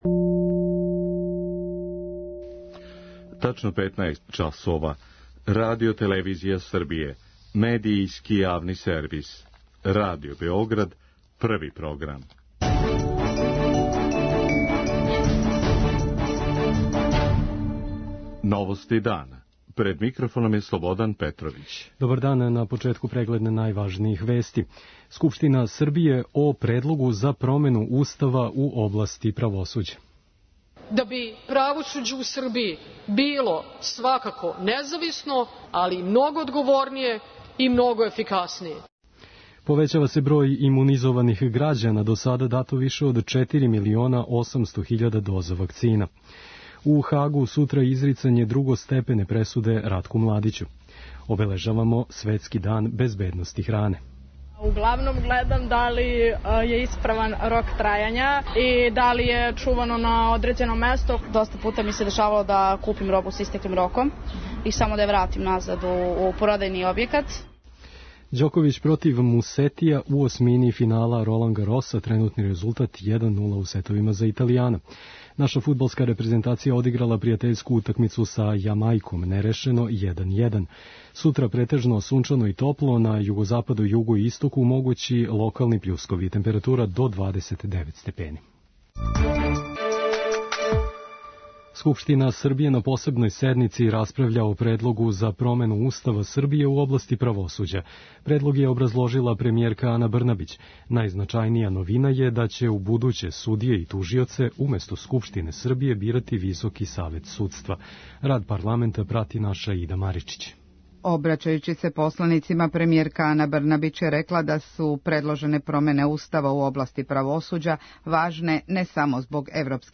Скупштина Србије, на посебној седници, расправља о предлогу за промену Устава Србије, у области правосуђа. Предлог за промену Устава образлажила је премијерка Ана Брнабић.
После одлучивања у Скупштини, очекује се референдум на којем ће се о изменама Устава изјаснити сви грађани. преузми : 5.45 MB Новости дана Autor: Радио Београд 1 “Новости дана”, централна информативна емисија Првог програма Радио Београда емитује се од јесени 1958. године.